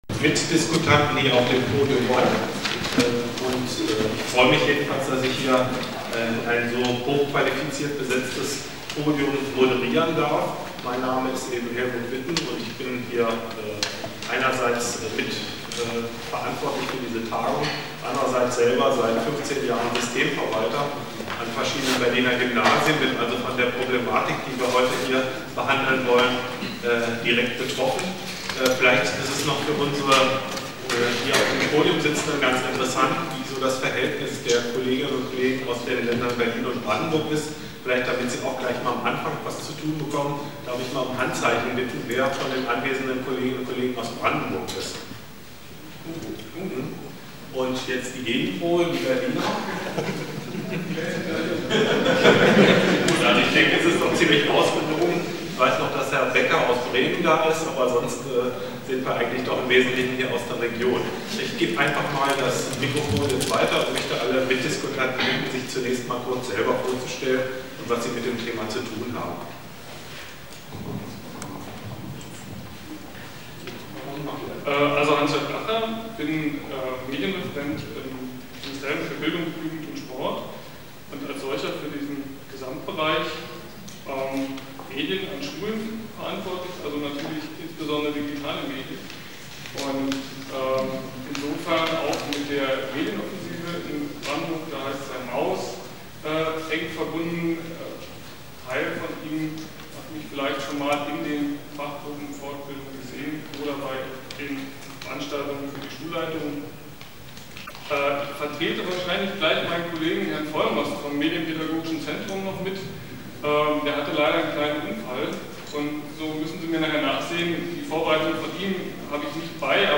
diskussion0.mp3